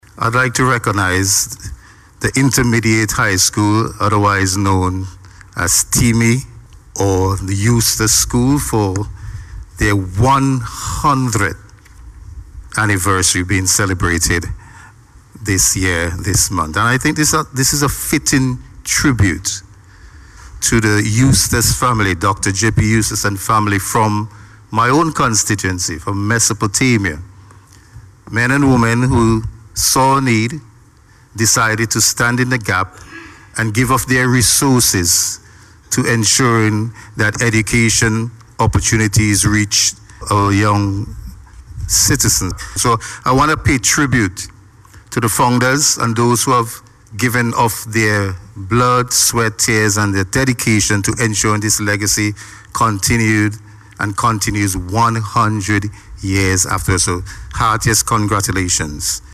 Speaking in Parliament this morning, Minister Jackson said he is looking forward to many more years of making lasting memories.